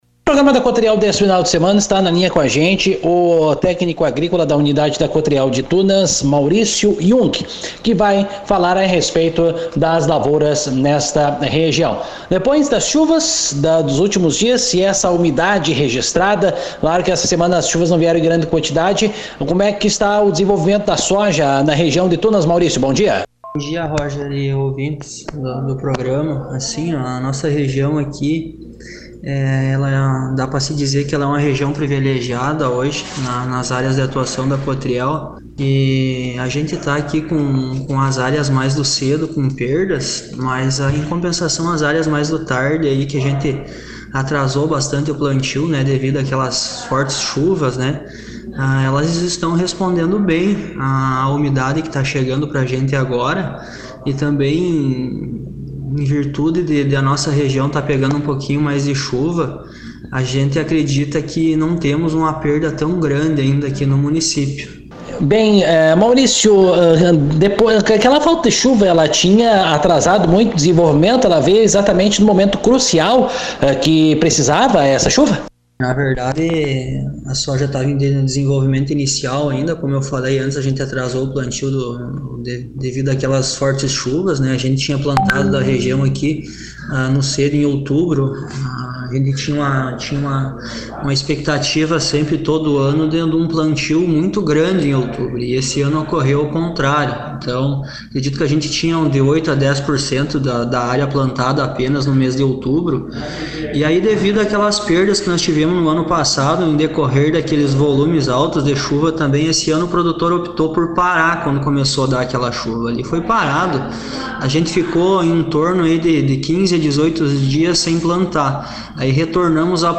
aqui a entrevista.